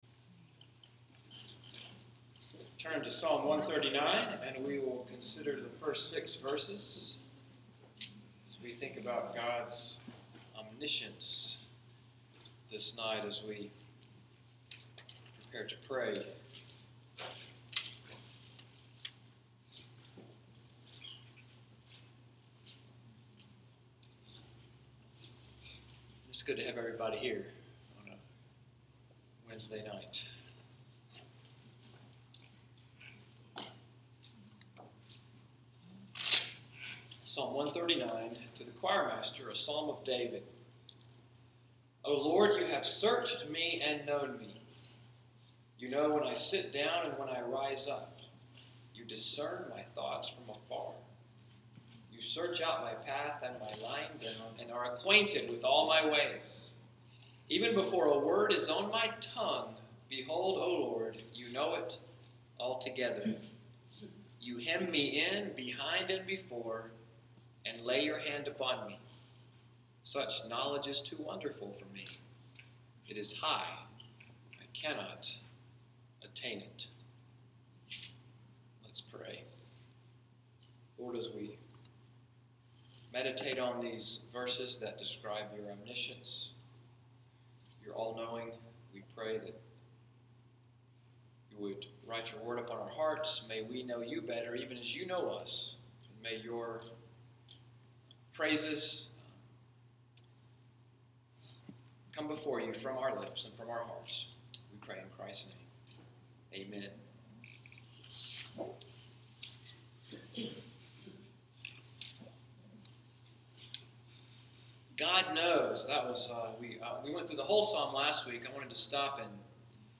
Wednesday Evening Bible Study at NCPC, “Psalm 139:1-6 Bible study“, July 27, 2016.